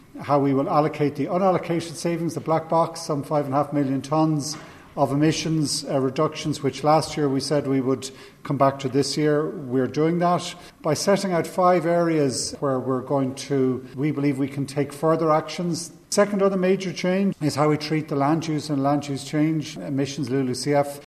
Eamon Ryan says there are two very significant elements to the plan…………..